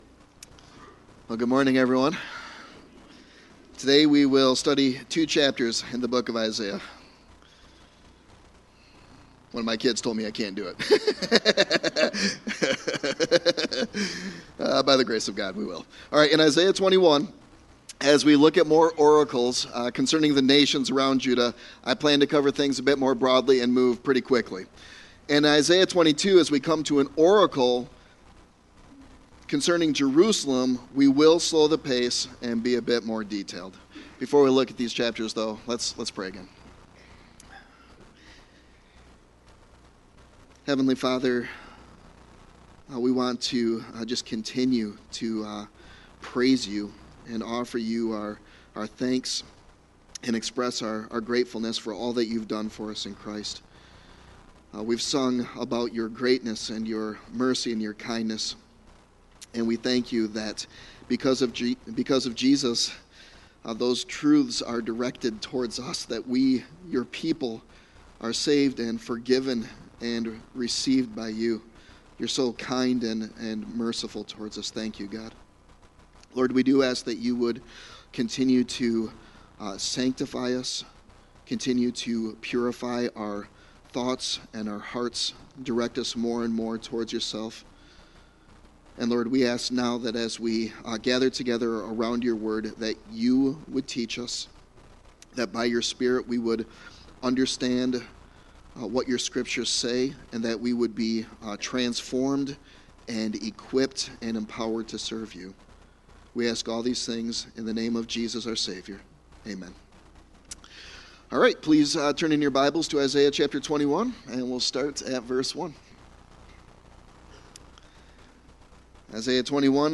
Sermon Text: Isaiah 21:1-22:25